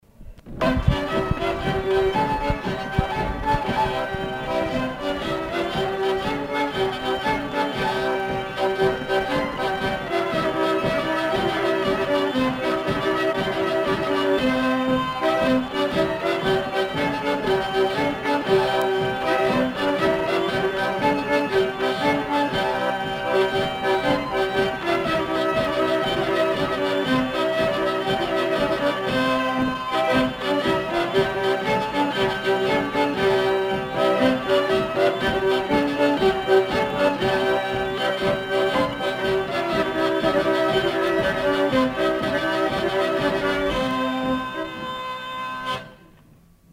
Aire culturelle : Savès
Département : Gers
Genre : morceau instrumental
Instrument de musique : accordéon diatonique ; violon ; vielle à roue
Danse : youska